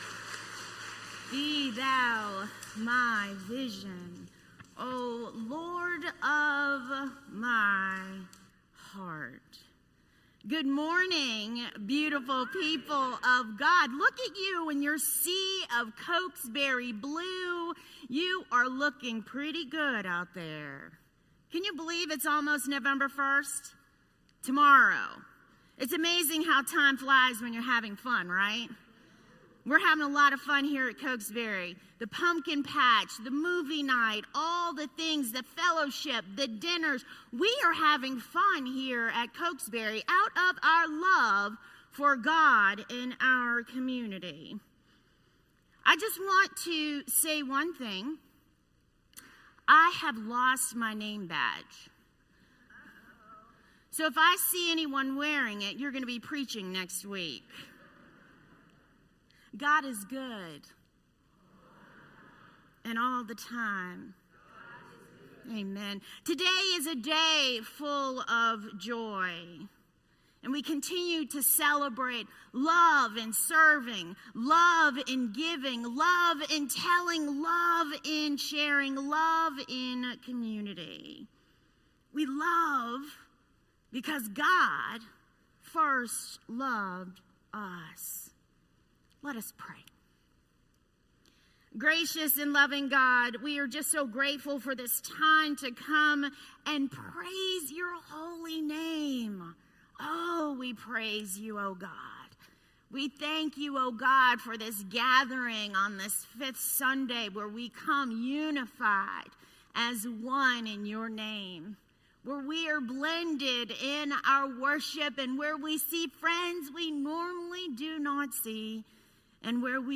Sermon Archives - Cokesbury Church